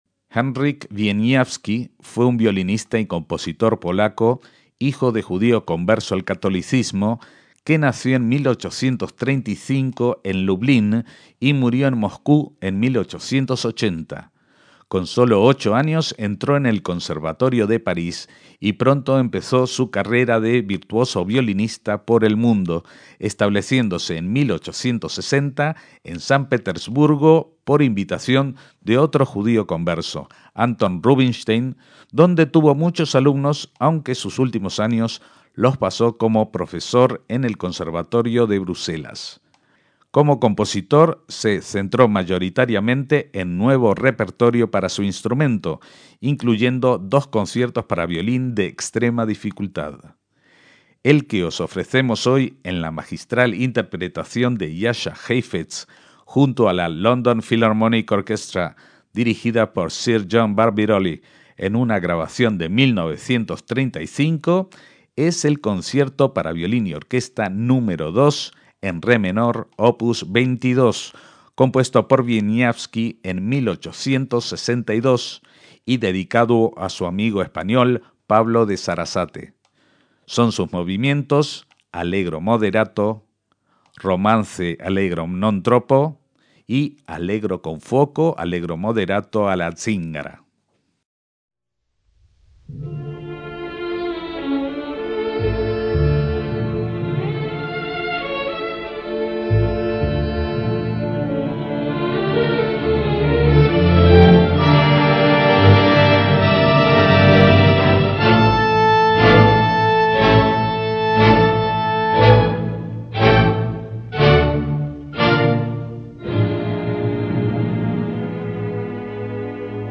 Heifetz interpreta a Wieniawski